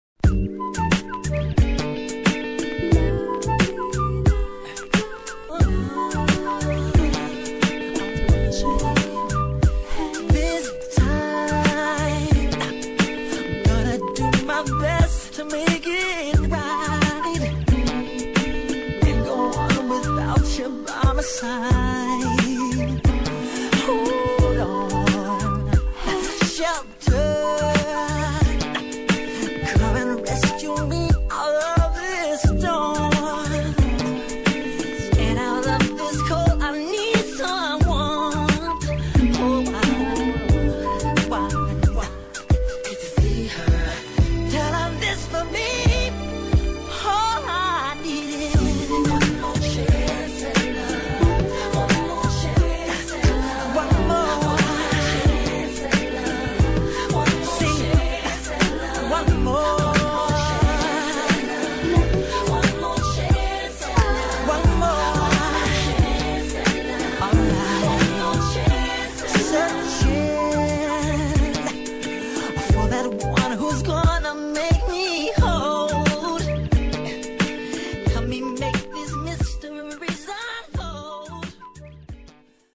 snip of background harmony cover